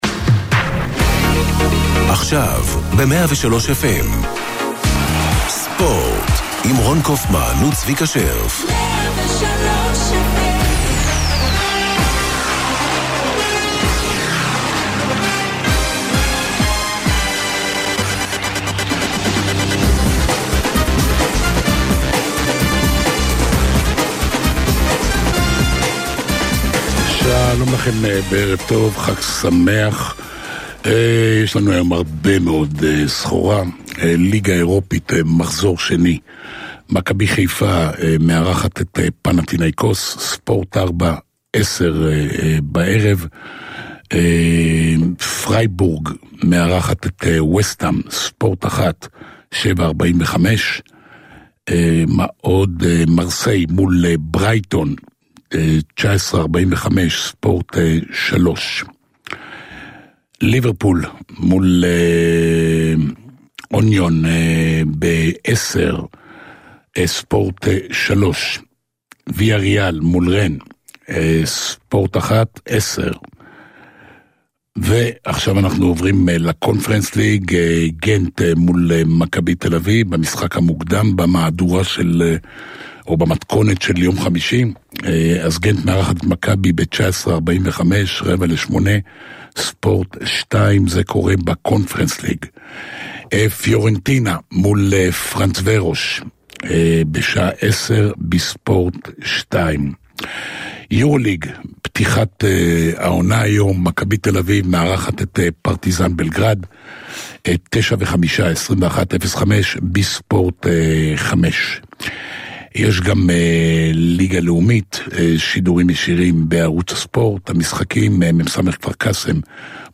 ריאיונות עם מאמנים, שחקנים, בעלים של קבוצות ופרשנויות על משחקים בארץ וברחבי העולם.